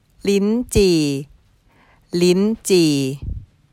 ライチは、タイ語では、”リンチー”と呼ばれます。
↓タイ語の発音はこちら。